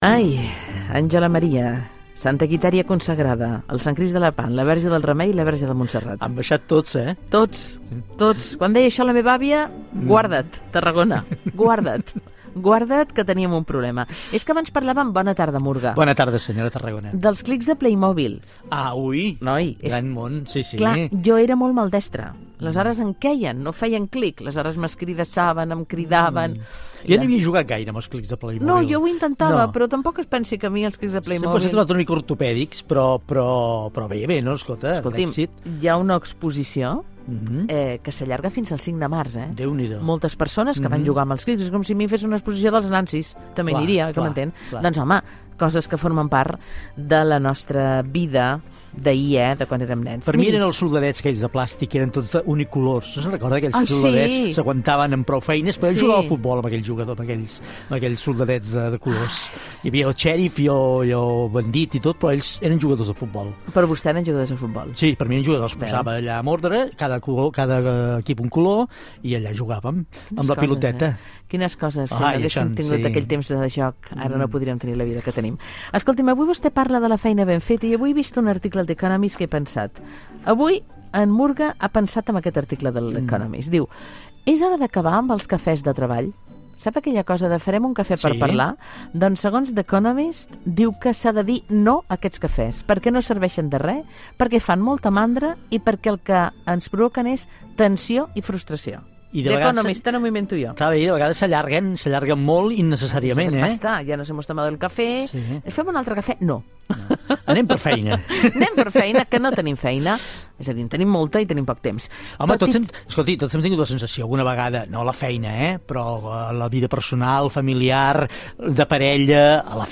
Diàleg inicial sobre els clicks de Playmobil, espai dedicat a la feina ben feta i comiat del programa Gènere radiofònic Entreteniment